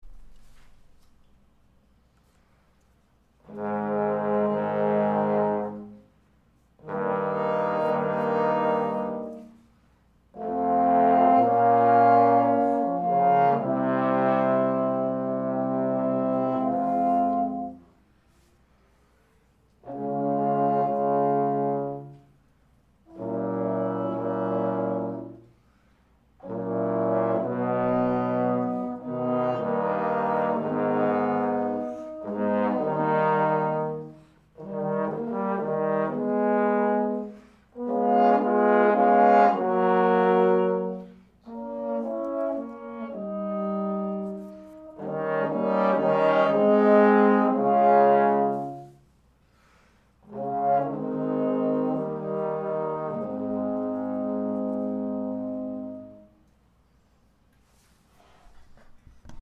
First, print out this PDF – it’s the score to the third movement of the Three Equali trombone quartet by Beethoven…
5. First part played on cup mute and fourth part played on a small mouthpiece